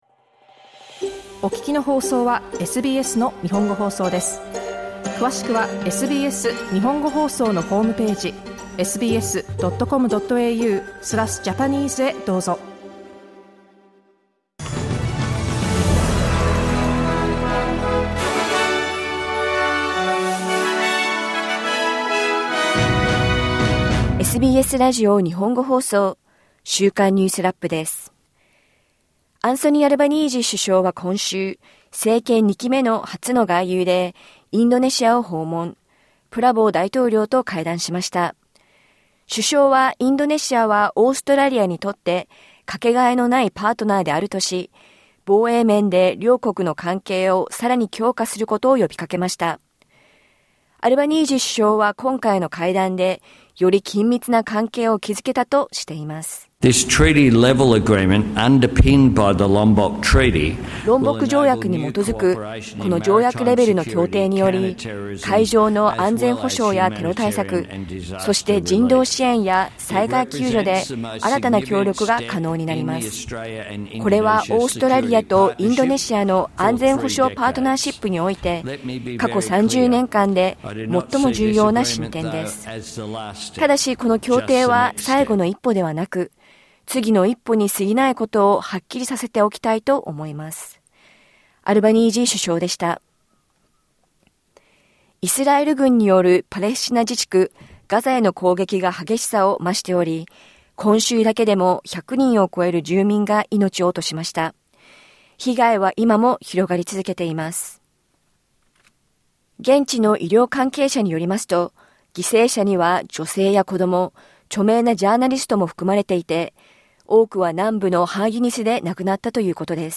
イスラエル軍によるパレスチナ自治区ガザへの攻撃が激化しており、多くの住民が犠牲となっています。新しい連邦内閣の就任式が行われた今週、自由党とグリーンズの党首に、それぞれ女性議員が選ばれました。1週間を振り返るニュースラップです。